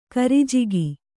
♪ karijigi